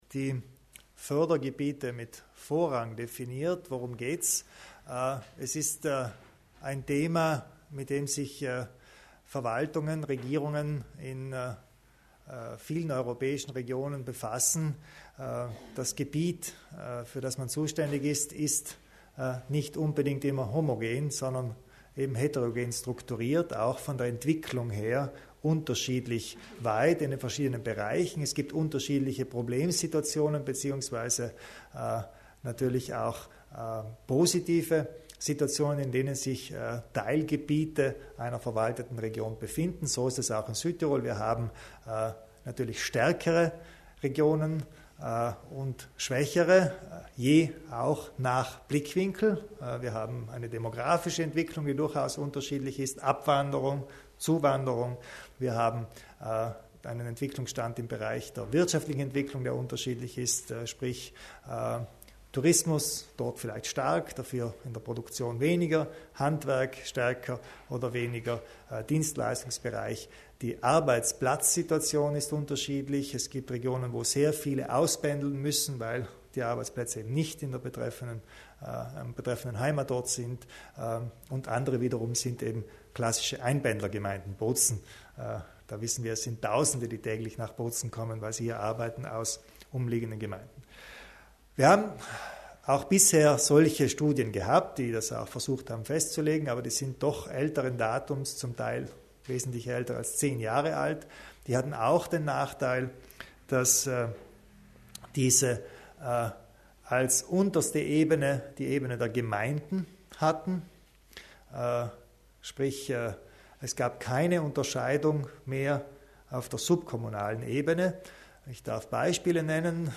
Landeshauptmann Kompatscher zur den Projekten für die Fördergebiete mit Vorrang